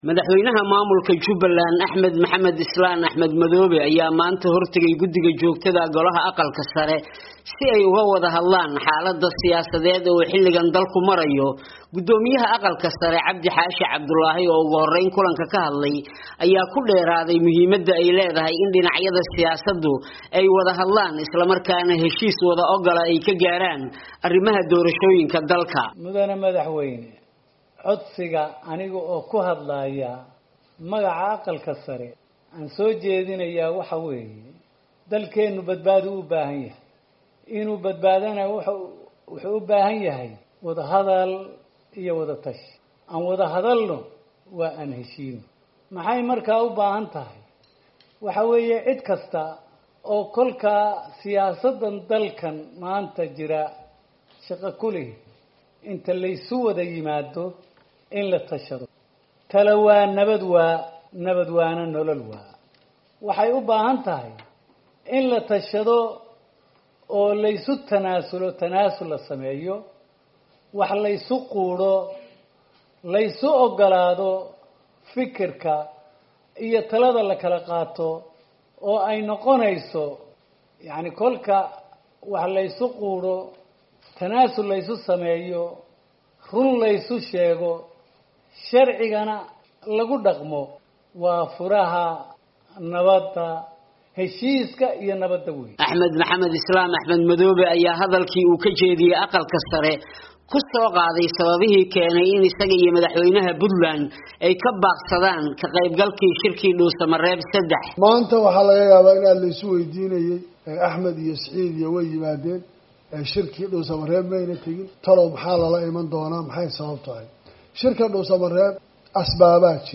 Axmed Maxamed Islaam Axmed Madoobe oo ku sugan Muqdishu ayaa maanta khudbad u jeediyey xubno ka tirsan xidhibaannada Aqalka Sare ee Soomaaliya.